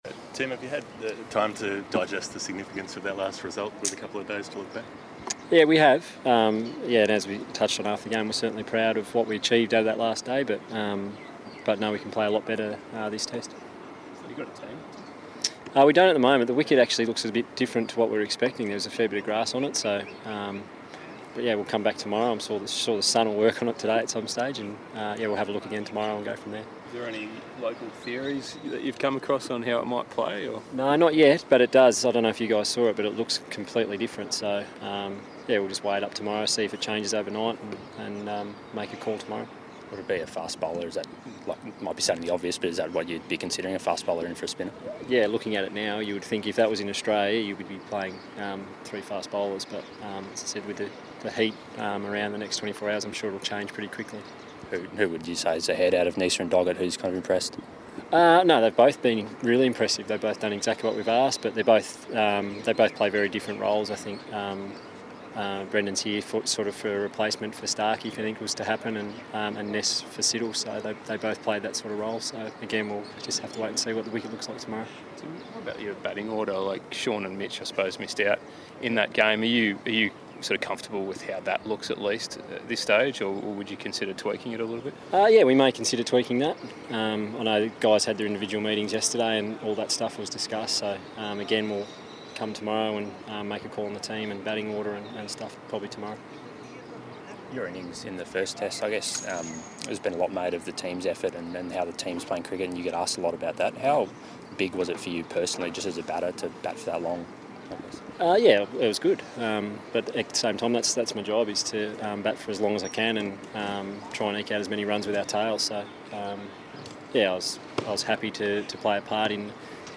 Australian Test captain, Tim Paine, and Mitchell Starc spoke to the media ahead of the second Test against Pakistan which starts in Abu Dhabi tomorrow.